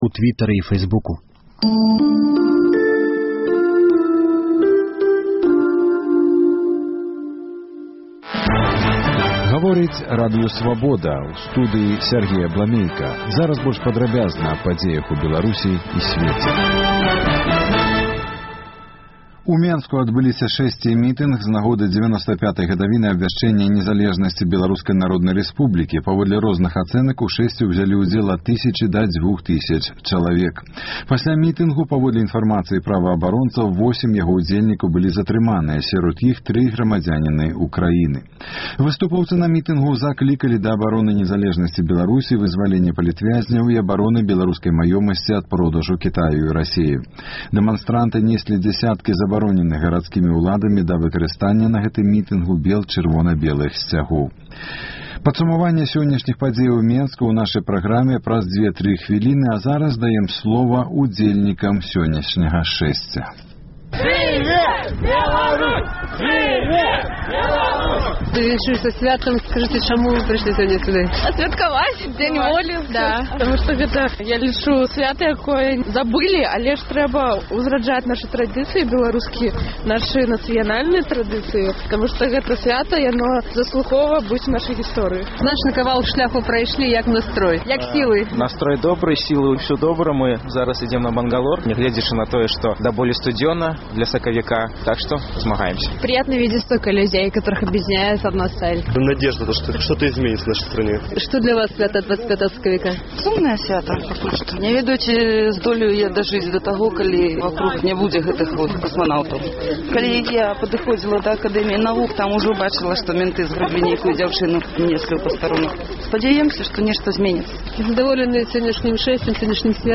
Сёлета на Дзень Волі на сайце Свабоды — жывы рэпартаж зь мітынгу і шэсьця ў Менску.